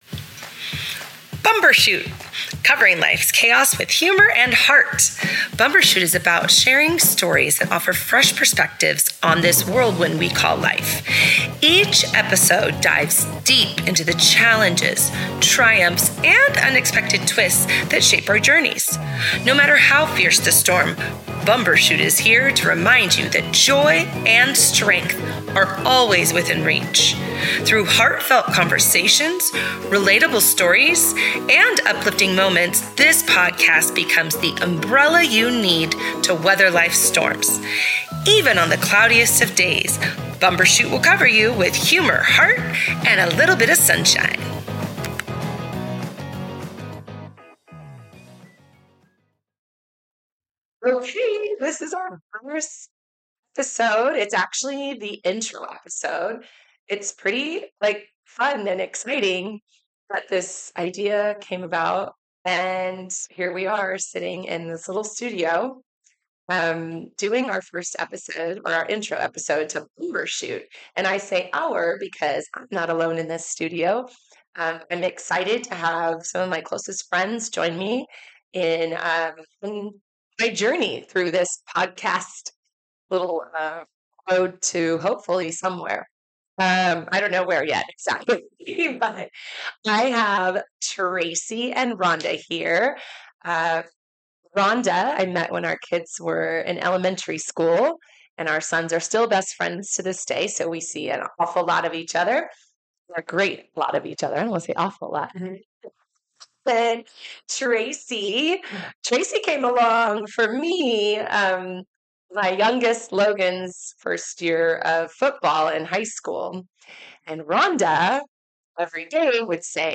(Sorry for the little skips... we're learning our way! Already figured out how to get a better sound for next week!